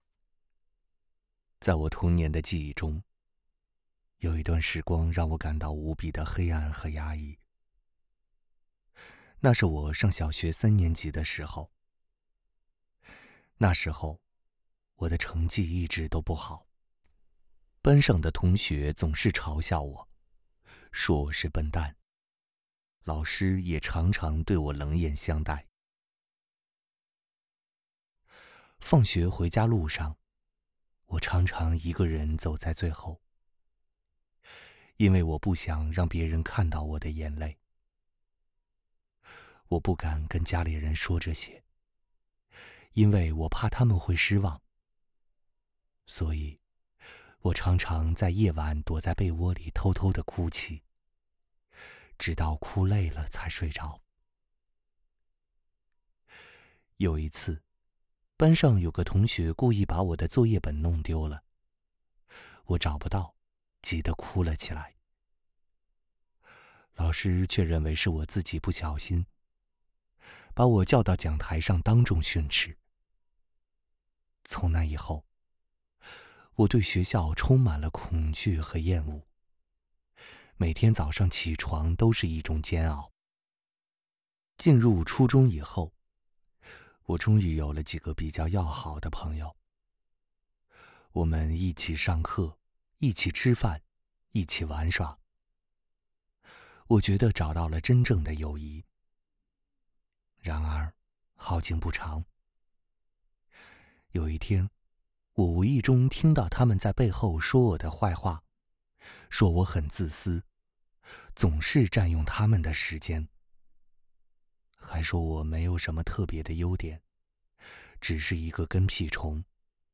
Chinese_Male_005VoiceArtist_20Hours_High_Quality_Voice_Dataset
Sad Style Sample.wav